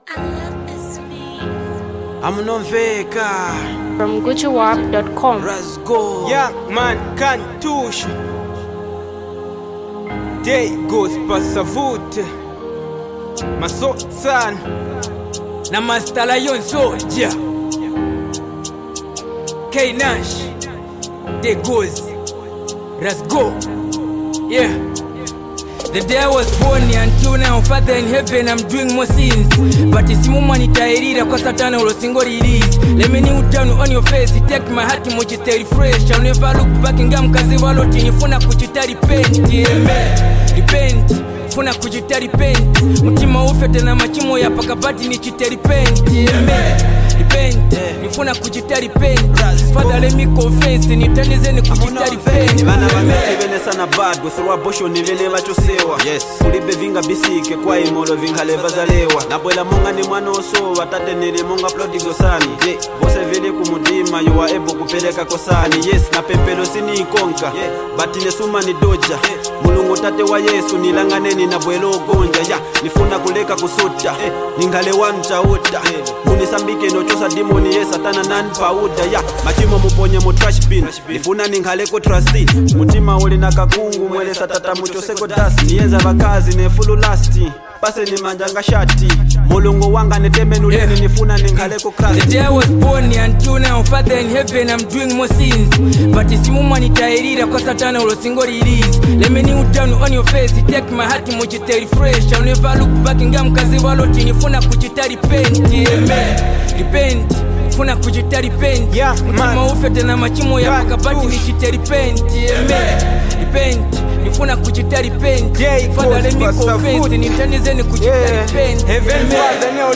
Eastern rap sensations